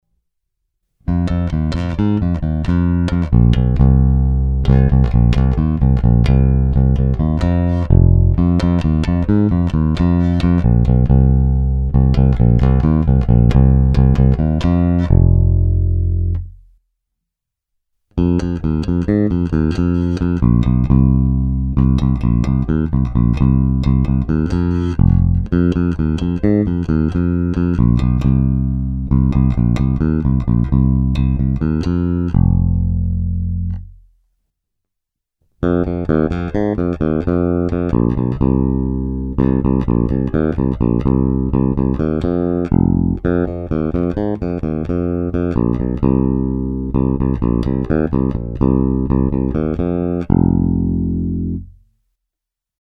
Není-li uvedeno jinak, následující nahrávky jsou vyvedeny rovnou do zvukové karty a vždy s plně otevřenou tónovou clonou a s korekcemi v nulové poloze, následně jsou jen normalizovány, jinak ponechány bez úprav.
Ukázka 1 ve stejném pořadí jako výše